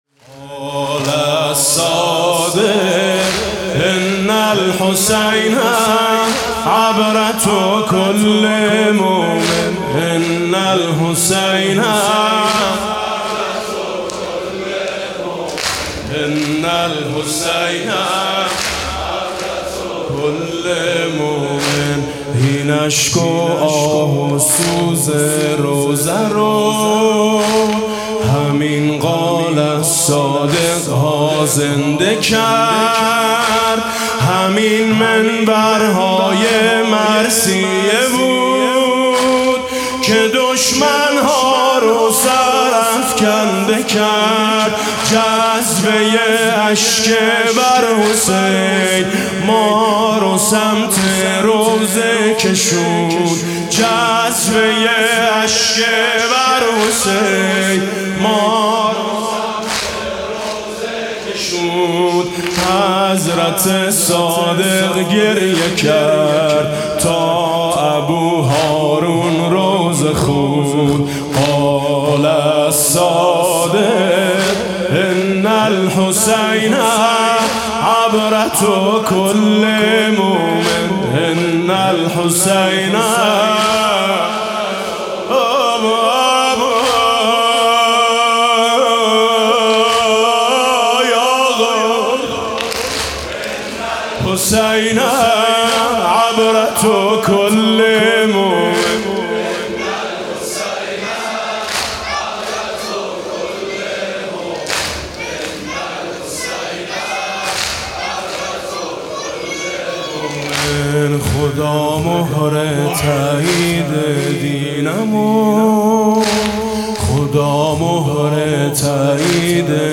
به مناسبت شهادت امام صادق(علیه السلام)